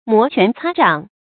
摩拳擦掌 注音： ㄇㄛˊ ㄑㄨㄢˊ ㄘㄚ ㄓㄤˇ 讀音讀法： 意思解釋： 形容行動之前心情激動；情緒高昂；急不可待。